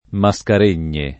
maSkar%n’n’e]) top. f. pl. (Afr.) — port. Mascarenhas [m9šk9r%n’9š]; ingl. Mascarene [^Skëriin]; fr. Mascareignes [maSkar$n’]